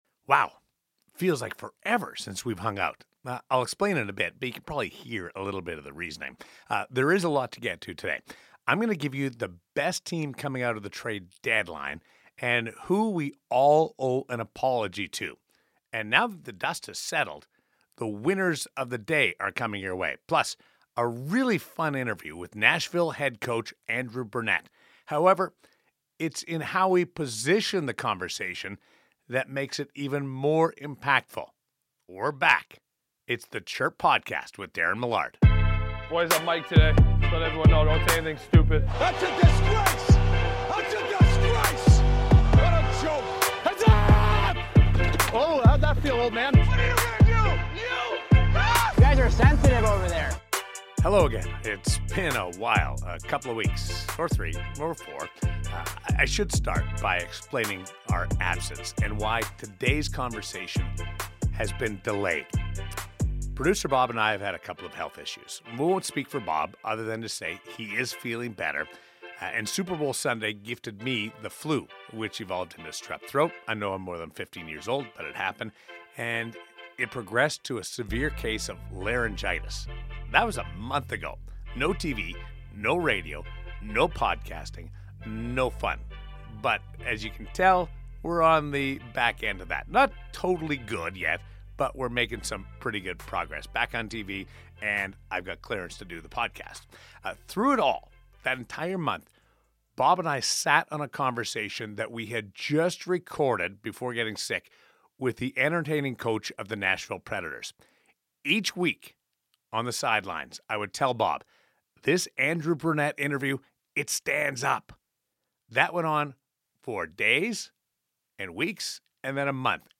Facebook Twitter Headliner Embed Embed Code See more options Daren is back and is joined by Nashville Predators coach Andrew Brunette (6:02). Brunette talks about the Predators season thus far, being in the playoff hunt, working with Barry Trotz, his coaching philosophy and his approach when communicating with players. After Brunette, Daren goes through the trade deadline moves and breaks down the teams he thinks positioned themselves best for the stretch run and the Stanley Cup Playoffs.